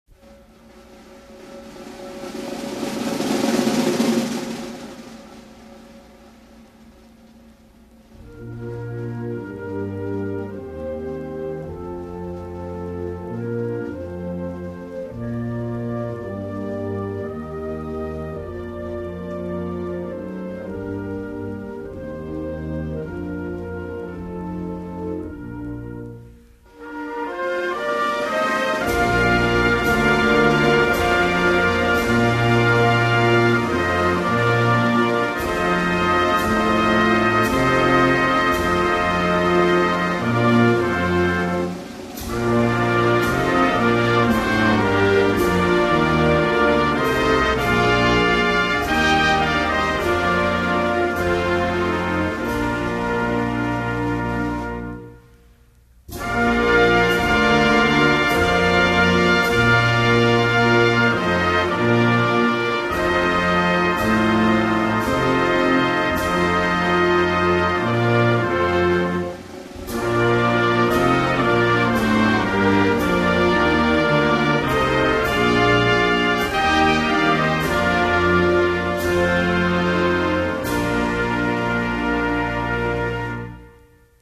Oben_am_jungen_Rhein,_by_the_U.S._Navy_Band.mp3